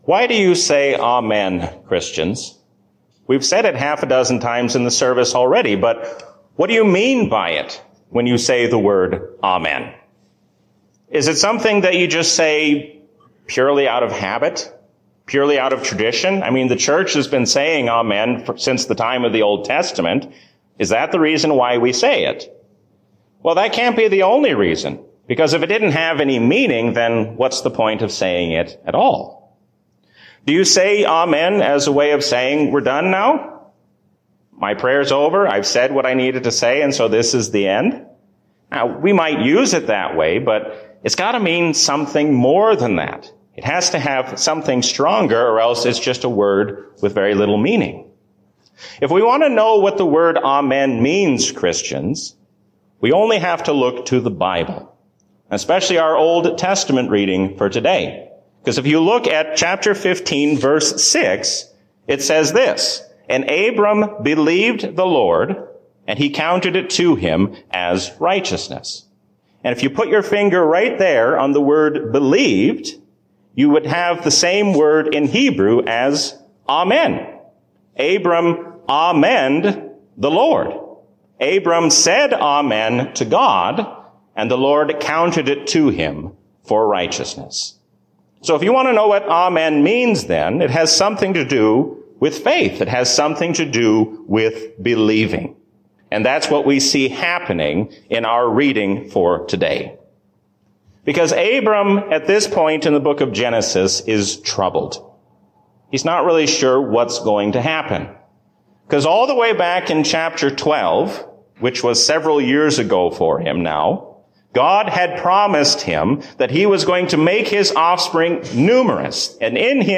A sermon from the season "Trinity 2021." Christ's righteousness is your righteousness.